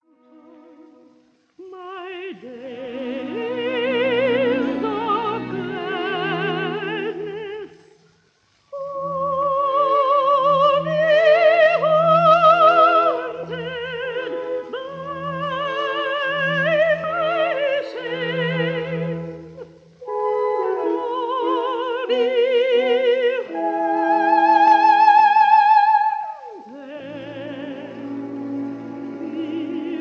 horn